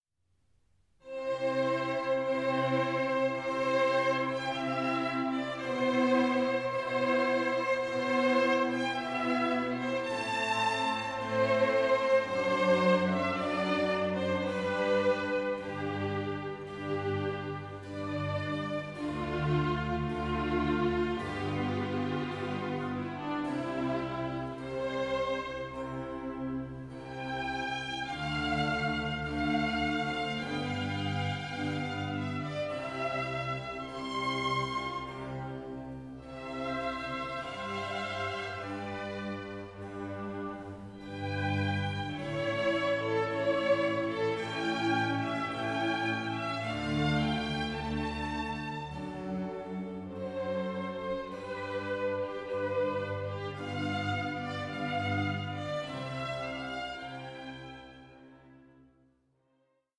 Concerto à 5 in G for flute, strings and continuo